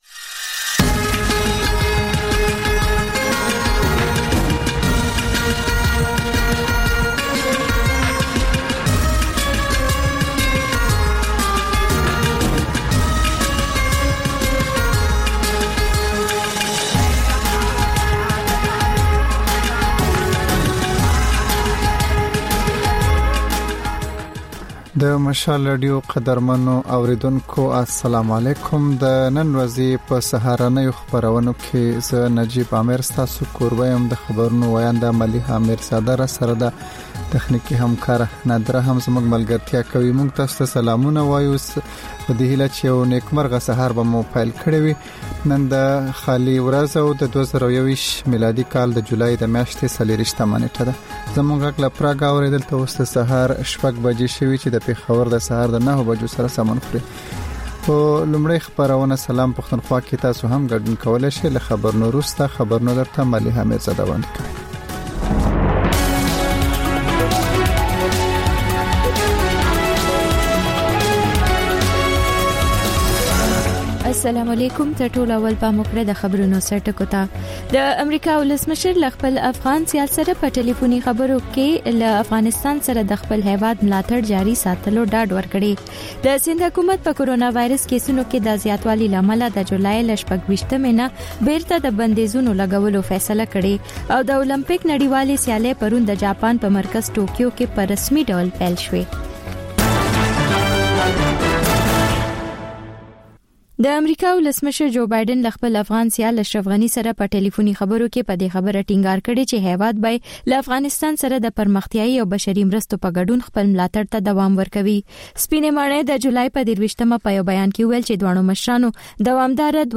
دا د مشال راډیو لومړۍ خپرونه ده چې په کې تر خبرونو وروسته رپورټونه، له خبریالانو خبرونه او رپورټونه او سندرې در خپروو.